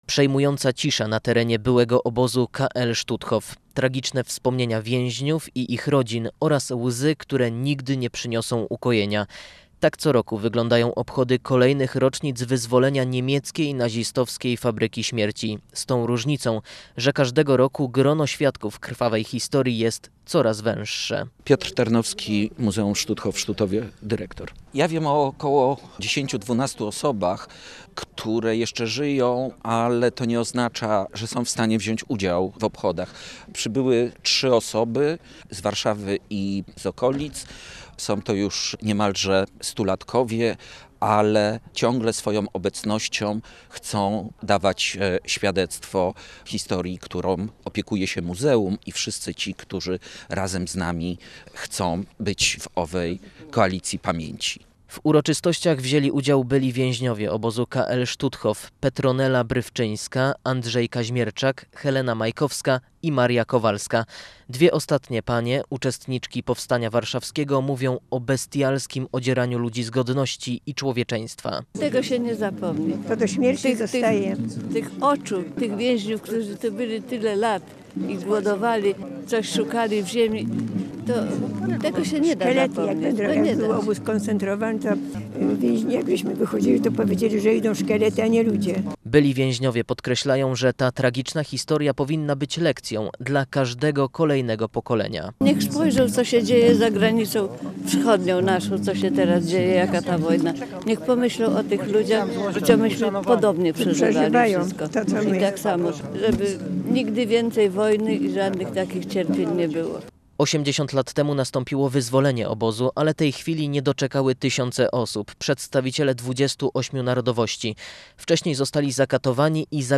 Marszałek w trakcie uroczystości powiedziała, że historia obozu przypomina, do czego prowadzi zorganizowana nienawiść, pogarda wobec drugiego człowieka i milczenie wobec zła.